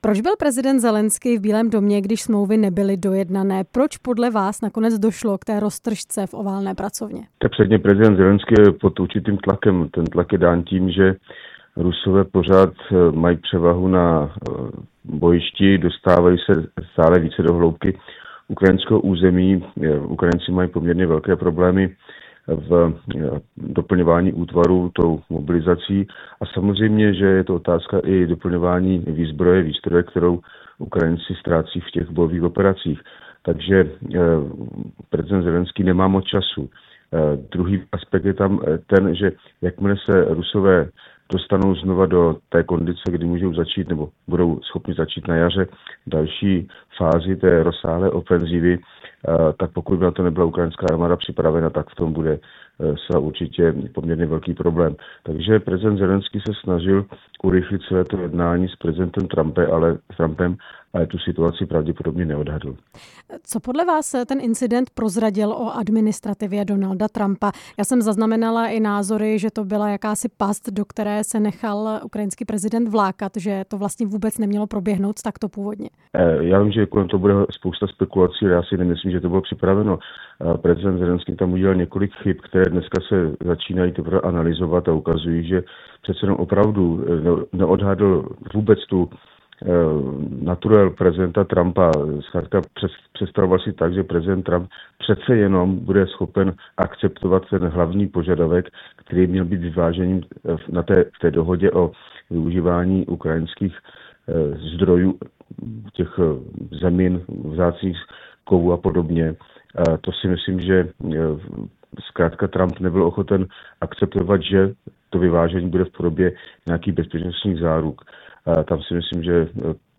Ve vysílání Radia Prostor jsme se na jednání ptali bývalého náčelníka generálního štábu Jiřího Šedivého.
Rozhovor s Bývalým náčelníkem generálního štábu Jiřím Šedivým